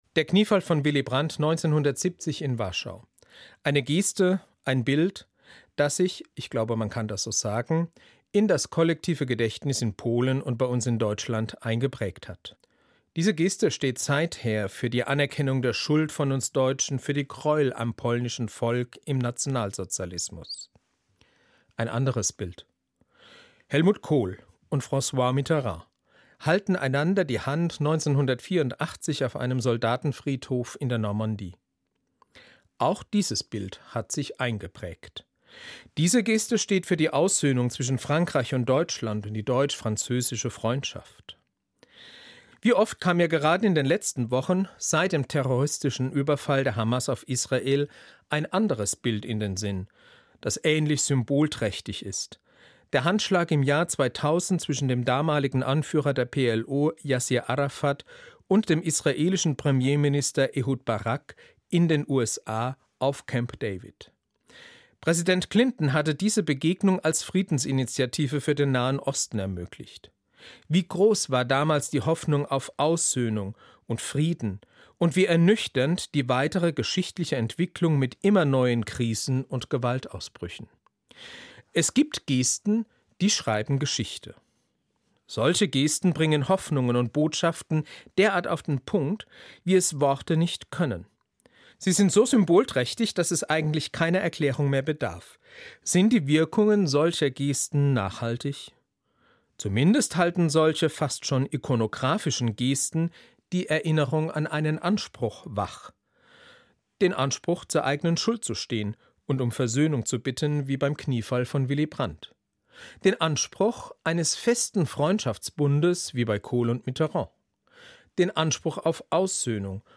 Eine Sendung von Dr. Udo Bentz, Erzbischof des Erzbistums Paderborn